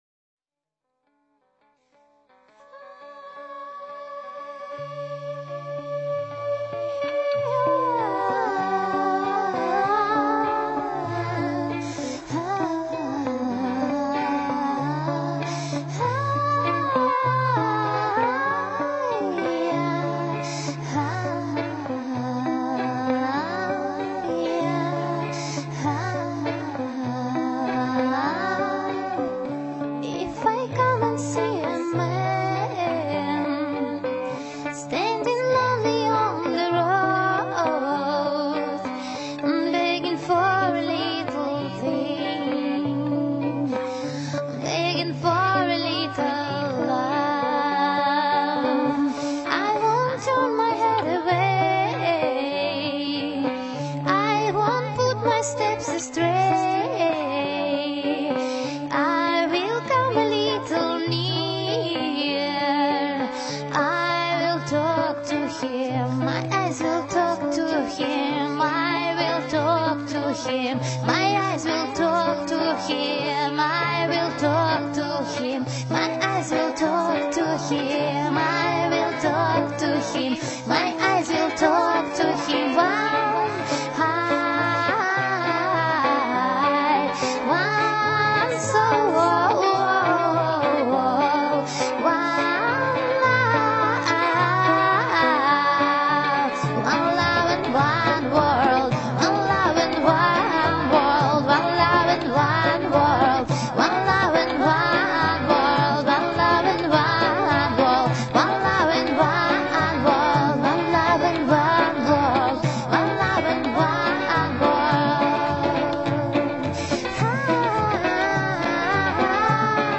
Und direkt daneben ist dann die Audioinstallation mit Gebeten aus verschiedenen Religionen und Friedensmelodien aus aller Welt – zu hören.
Audioinstallation mit Gebeten, Musik und Melodien ist unter anderem von Komponisten